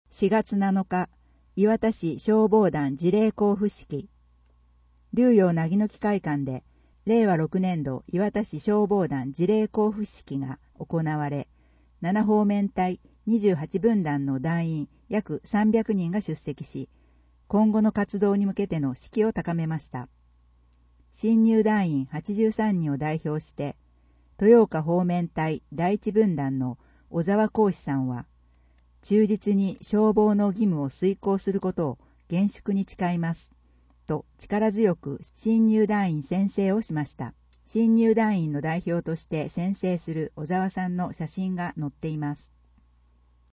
市内の視覚に障がいがある方のために、広報いわたの内容を録音した「声の広報」を制作していますが、声の広報をもとにインターネット上でも手軽に利用できるサイトとして公開しています。